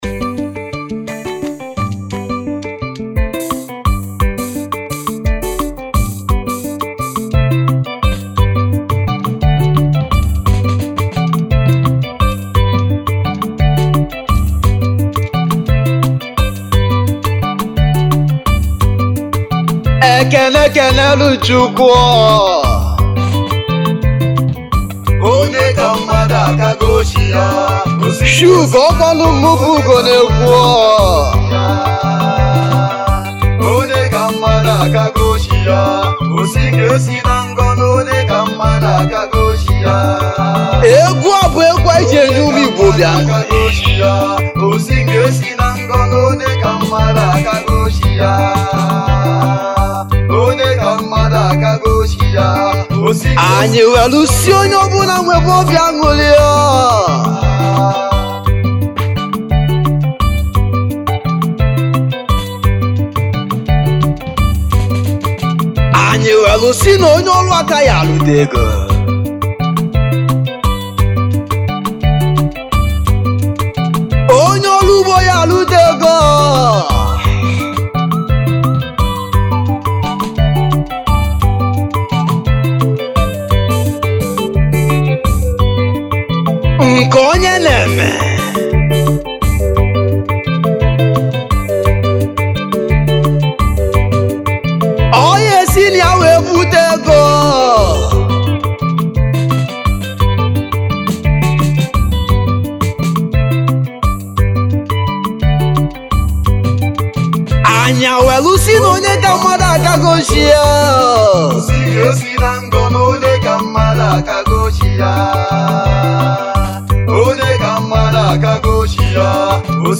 igbo highlife musician's